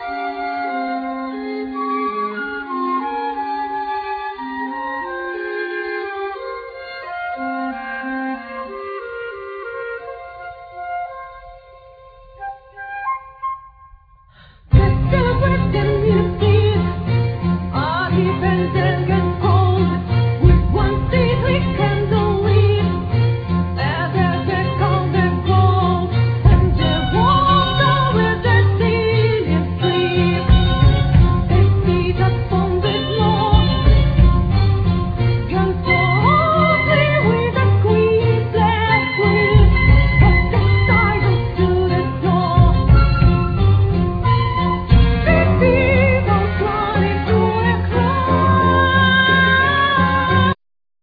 Flute
English horn
Clarinet,Vocals
Basson
Violin
Cello
Harp